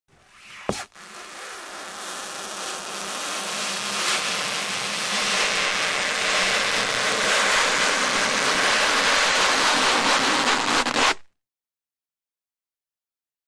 Rubber Stretch
Rubber Friction, One Long Rub